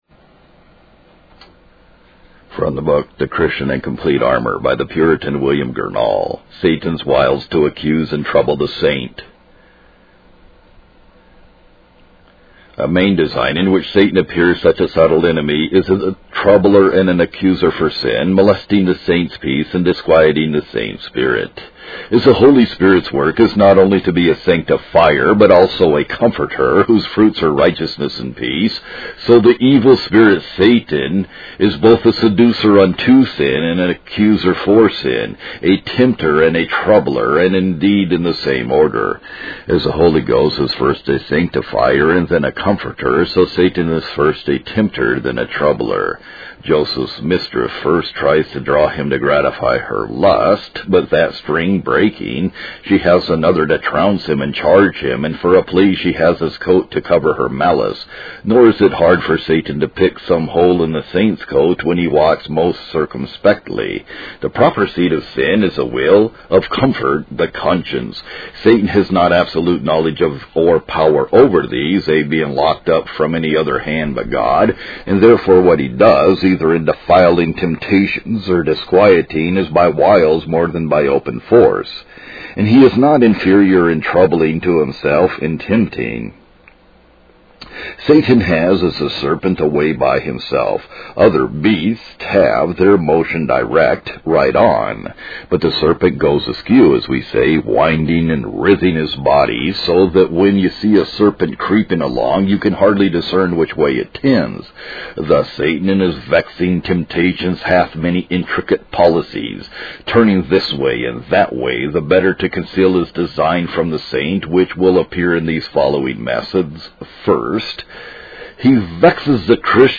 Satan's Wiles to Accuse and Trouble the Saint (Reading) by William Gurnall | SermonIndex